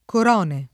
Corone [ kor 1 ne ] top. (Umbria)